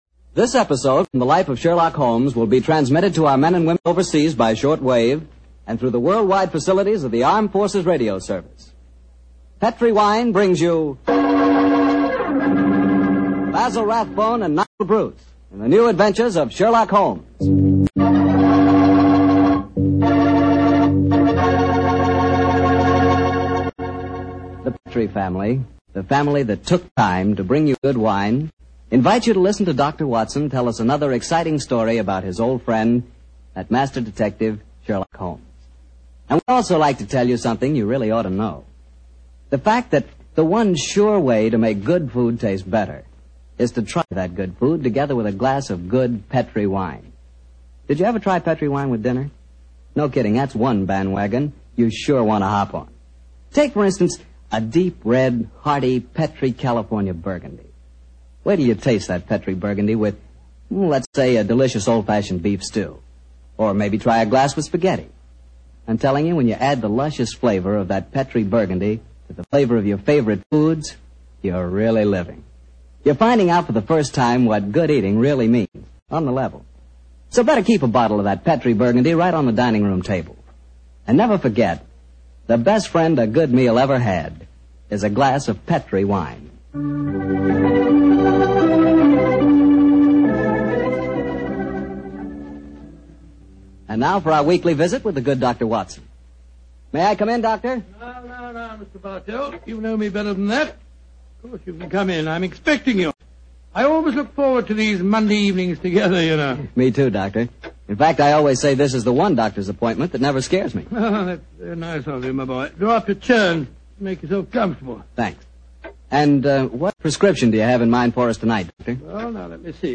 Radio Show Drama with Sherlock Holmes - The Out Of Date Murder 1945